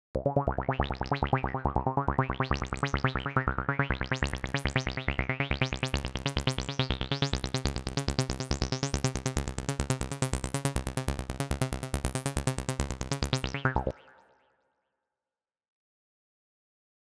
Der Acid-Sound ist bekannt für seine hypnotischen, sich wiederholende Basslinien, die durch die Manipulation des Cut-Off-Reglers und der Resonanz an Synthesizern erzeugt werden.
Eine Acid-Spur besteht oft aus kurzen, eher perkussiven Sounds kombiniert mit akzentuierten, etwas länger anhaltenden Noten.
Nachdem Sie diesen Schritten gefolgt sind, klingt ihre eigene Acid-Line vielleicht so: